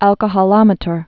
(ălkə-hô-lŏmĭ-tər) also al·co·hol·me·ter (ălkə-hôl-mētər, -hŏl-)